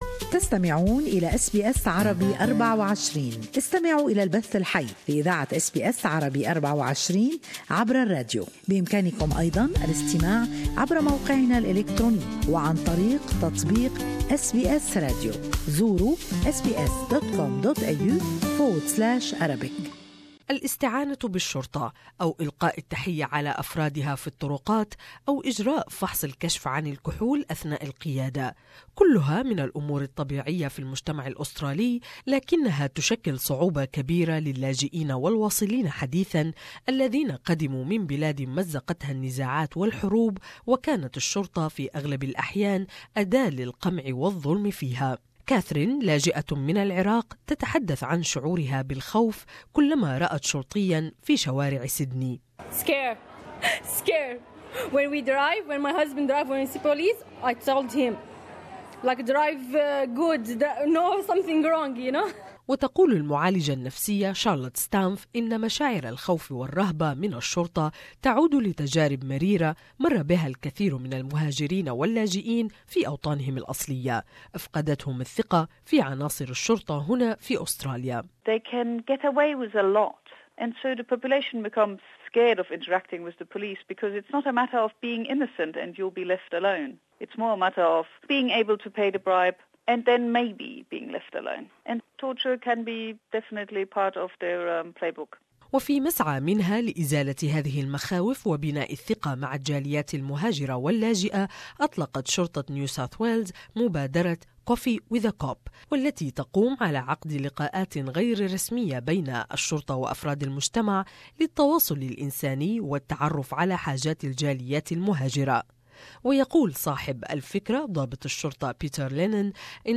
However, for some new arrivals it can be a fearful experience, reminding them of corruption or injustice in their homelands. A new initiative called Coffee with a Cop is trying to overcome old resentments towards police. more in this report